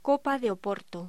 Locución: Copa de Oporto
voz